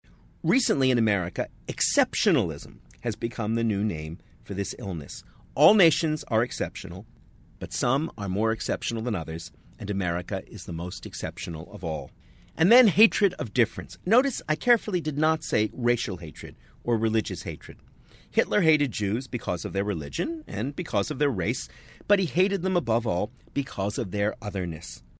【英音模仿秀】纳粹已成历史，狭隘心态仍在 听力文件下载—在线英语听力室